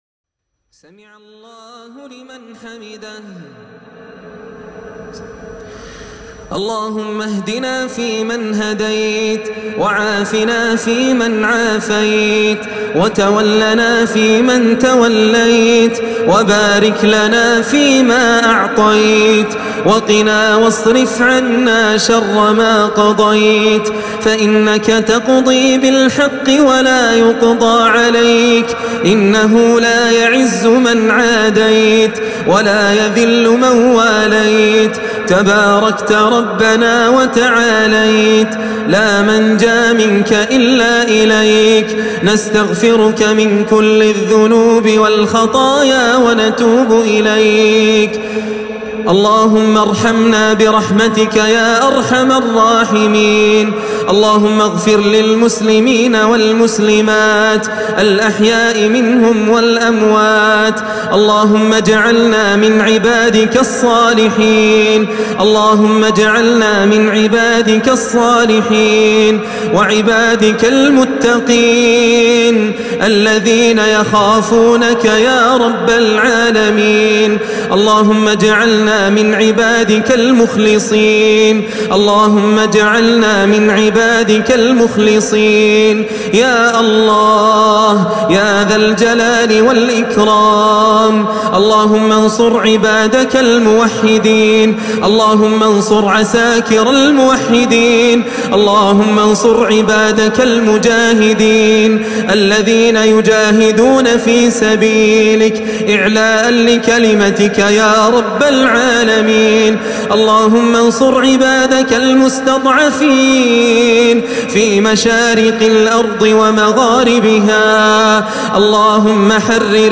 دعاء مؤثر.